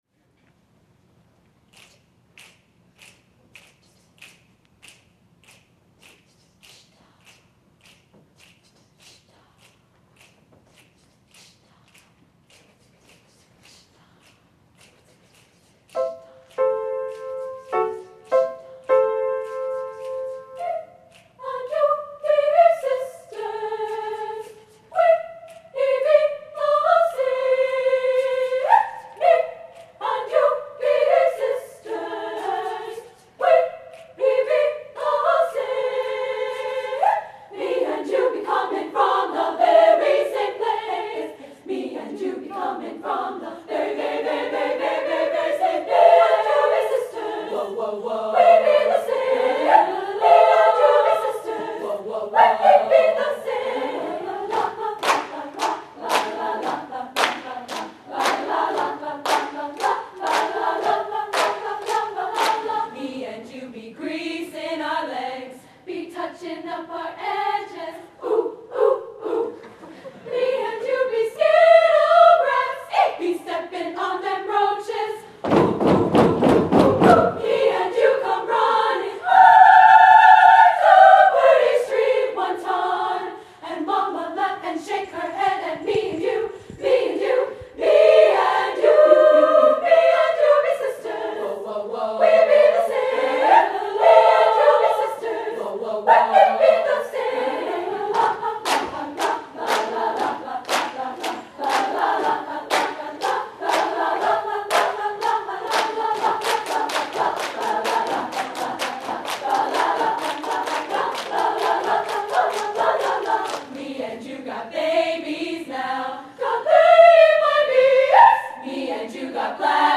for SSA Chorus (1998)
There is energy and humor in both the poetic and musical language.